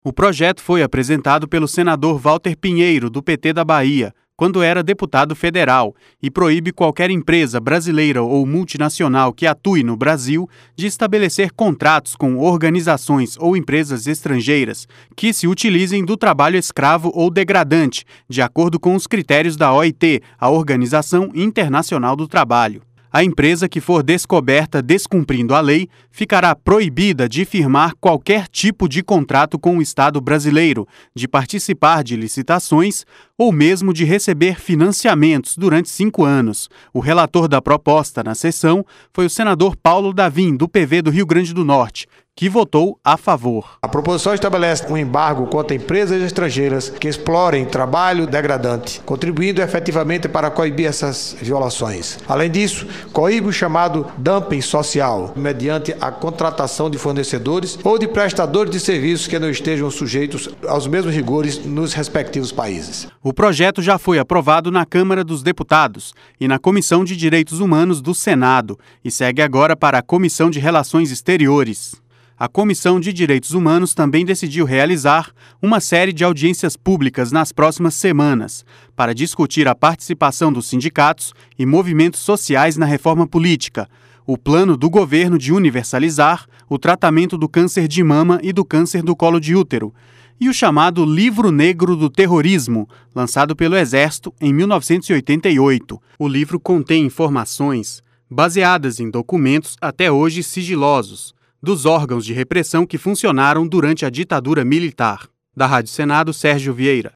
Senador Paulo Davim